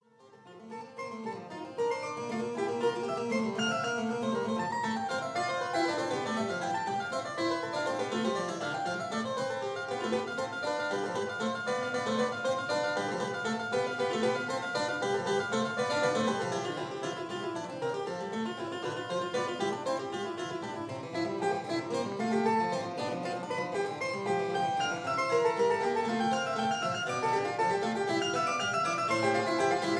harpsichord